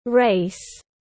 Cuộc đua tiếng anh gọi là race, phiên âm tiếng anh đọc là /reɪs/ .
Race /reɪs/